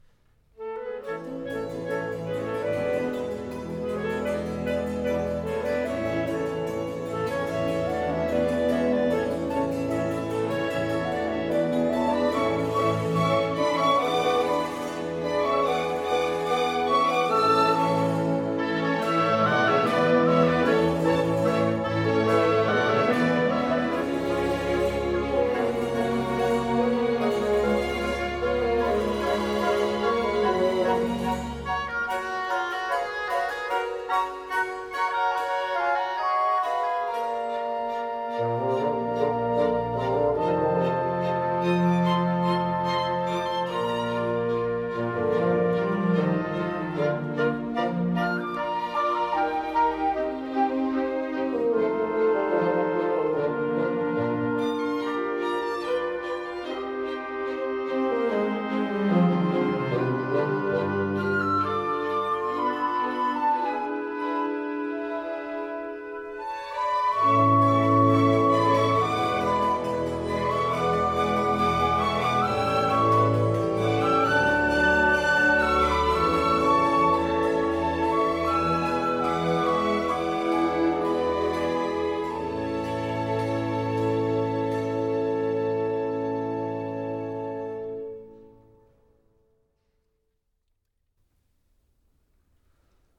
Rolf Liebermann: Im Aargäu sind zwei Liebi – Two lovers in Aargau. Andantino